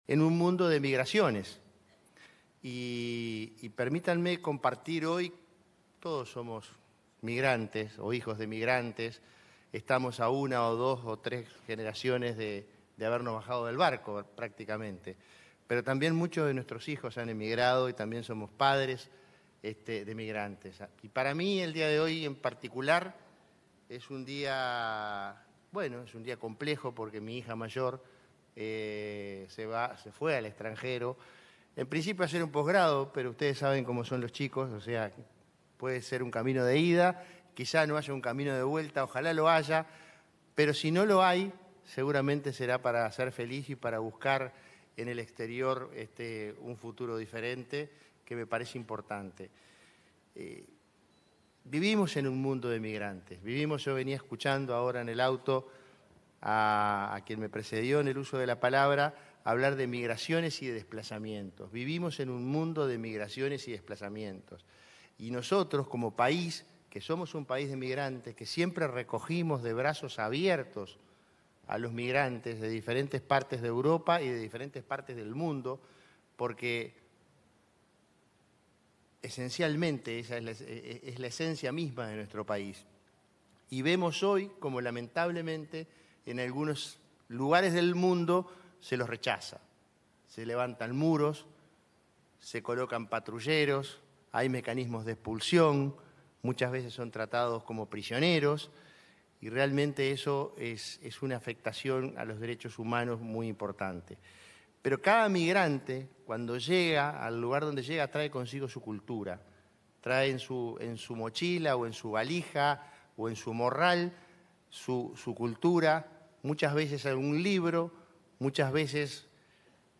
Palabras del prosecretario de Presidencia, Jorge Díaz
Palabras del prosecretario de Presidencia, Jorge Díaz 10/09/2025 Compartir Facebook X Copiar enlace WhatsApp LinkedIn El prosecretario de Presidencia, Jorge Díaz, se expresó en la inauguración de la 18.ª Feria Internacional de Promoción de la Lectura y el Libro de San José, que se realizó en el teatro Macció, ubicado en la capital maragata.